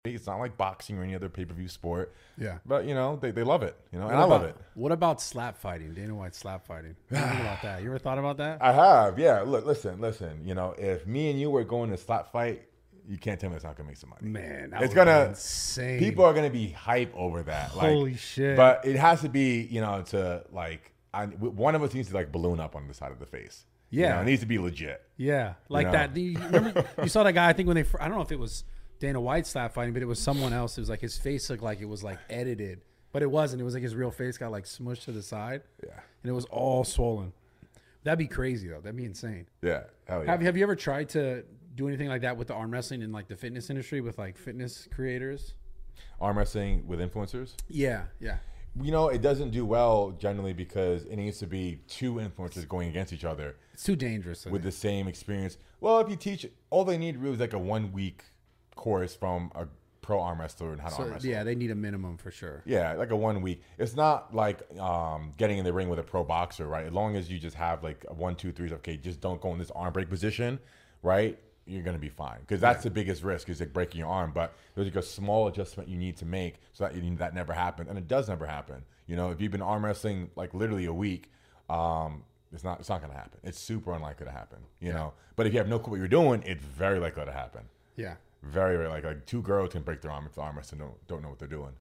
Get ready to explore the wild world of slap fighting and arm wrestling! In this captivating clip, we hear from the one and only Larry Wheels, a powerhouse who holds multiple world records in powerlifting and dabbles in various sports.
Larry certainly has, and he delves deep into what makes it so exhilarating and potentially profitable. From the epic facial reactions of competitors to the adrenaline rush of arm wrestling influencers, this engaging conversation highlights the thrill of these unique sports.